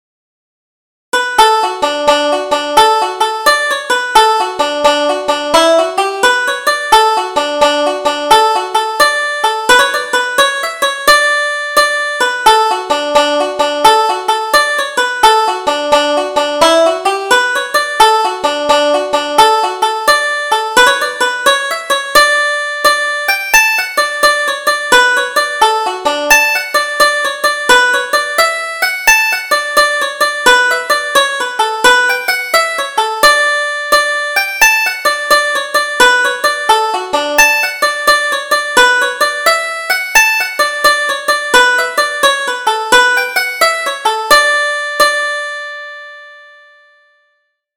Double Jig: Don't Be Teasing